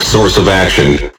完美适用于打造震撼的低音、丰富的节奏纹理和地下音乐氛围。